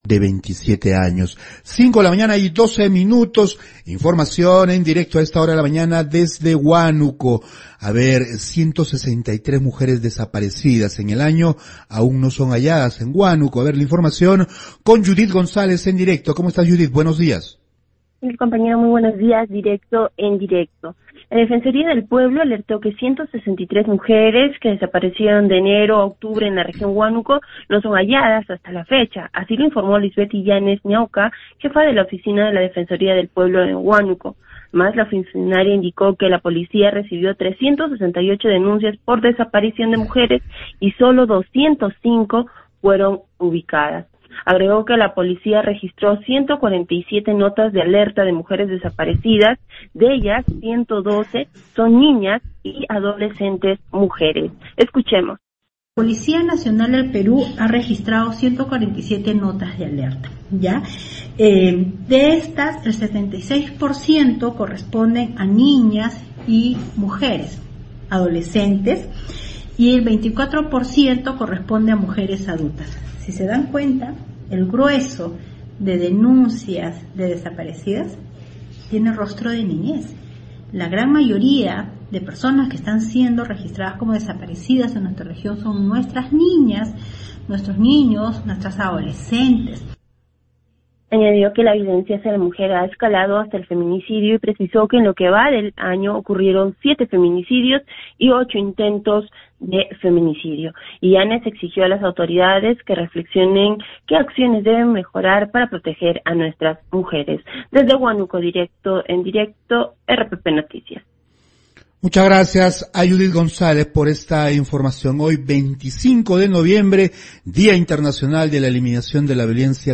por RPP Radio